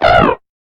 Cri de Bekaglaçon dans sa forme Tête de Gel dans Pokémon HOME.
Cri_0875_Tête_de_Gel_HOME.ogg